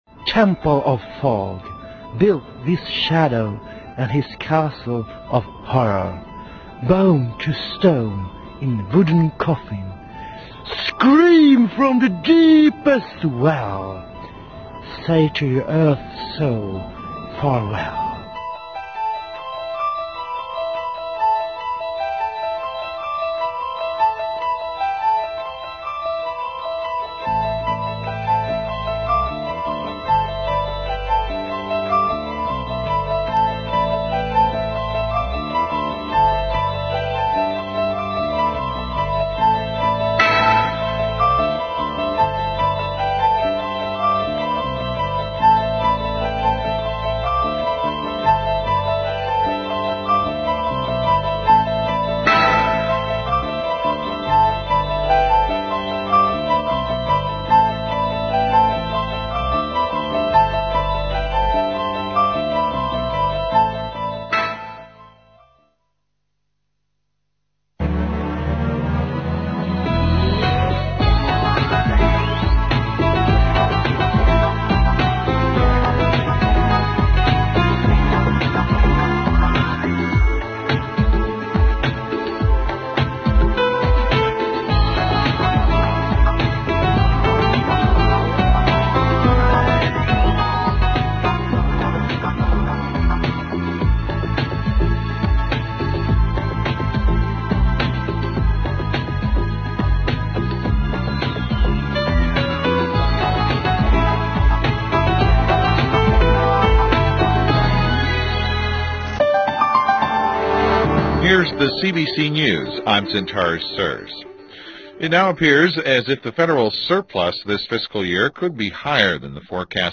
Bei einigen Rapporten habe ich kurze Empfangsdemos als mp3PRO erstellt. So kann man sich ein genaueres Bild über die Klangqualität machen.